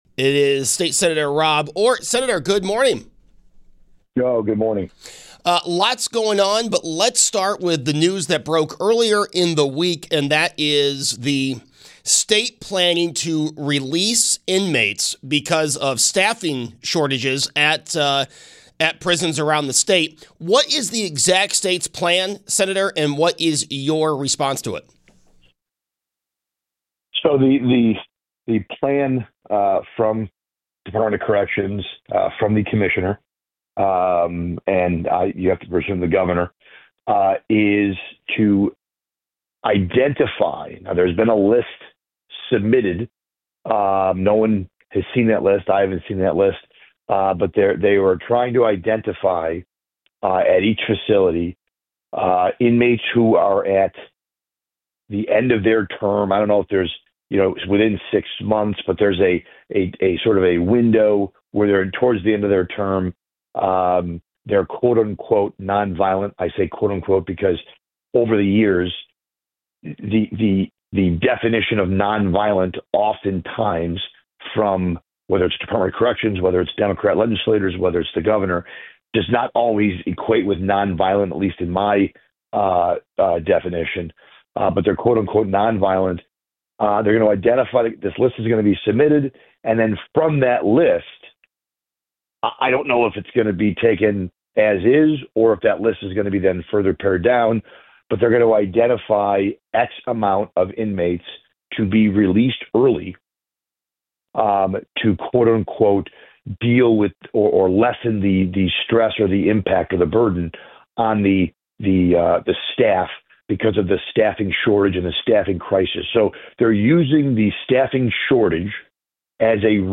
Minority Leader in the NYS Senate State Senator Rob Ortt joins the show to discuss the State Budget, state releasing inmates, and more.